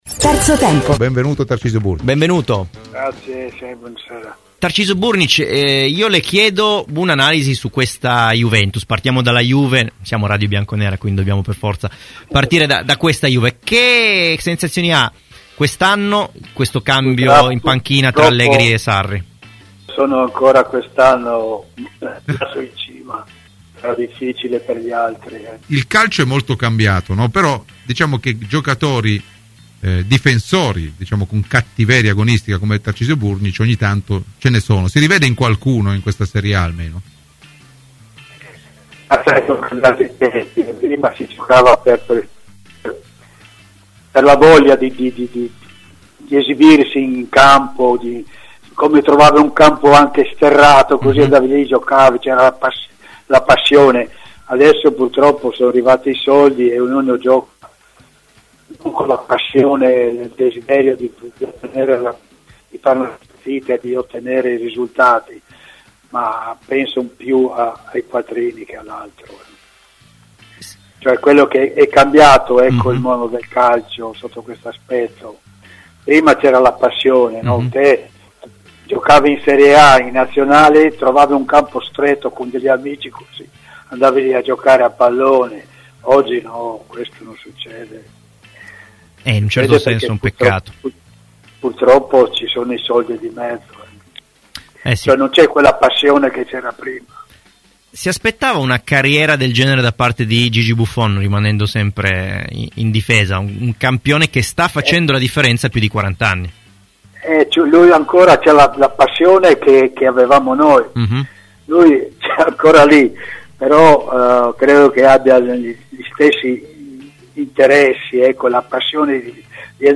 Ai microfoni di Radio Bianconera, nel corso di ‘Terzo Tempo, è intervenuto Tarcisio Burgnich: “La Juve è ancora in cima quest’anno, sarà difficile per gli altri.